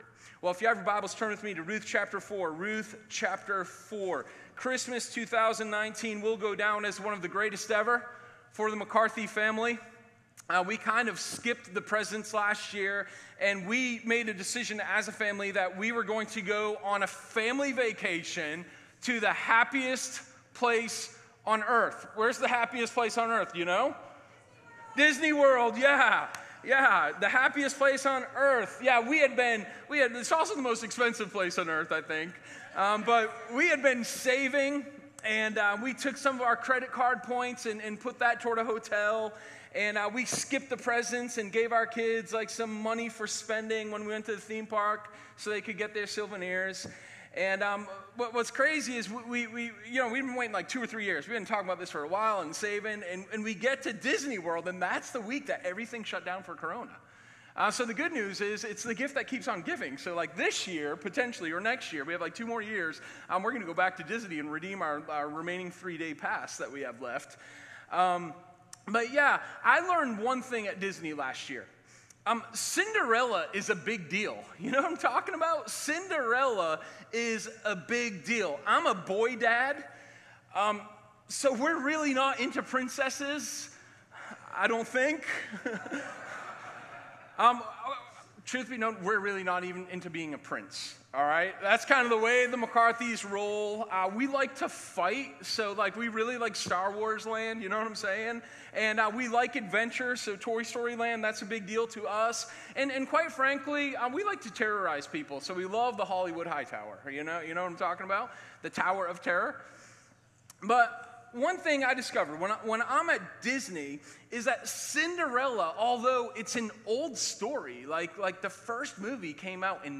Sermon1220_A-Loving-Redemption.m4a